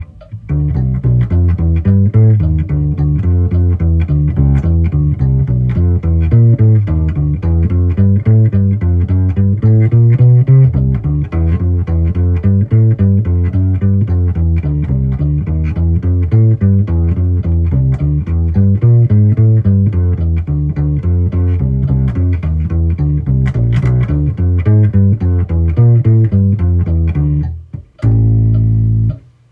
この泥臭さを少しだけマイルドにしたいのでBP-100のボリュームを50%に下げてみる。
ちょっとマイルドにしすぎた感じはあるが、ピエゾで拾ってほしい弦の当たる音を少し拾ってくれるだけでかなり雰囲気は変わった。